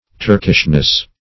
Meaning of turkishness. turkishness synonyms, pronunciation, spelling and more from Free Dictionary.